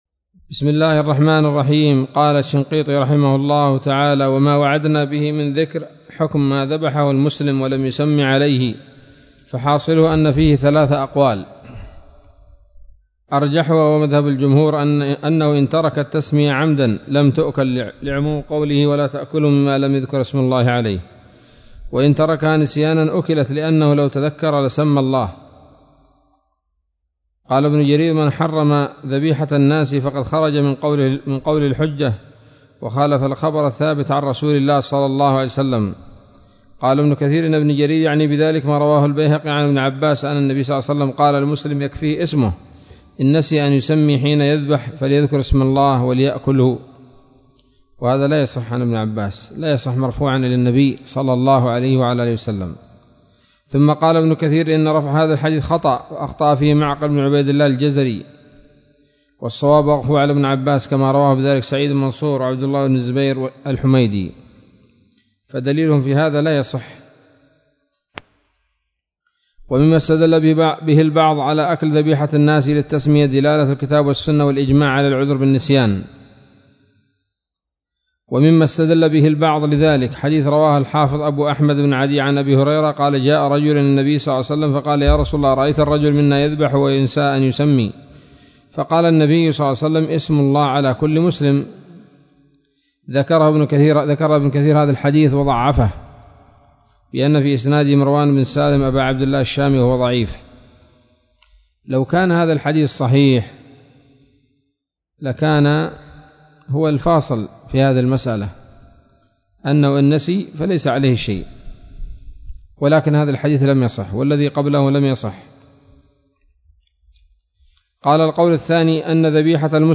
الدرس الخامس والثلاثون من دفع إيهام الاضطراب عن آيات الكتاب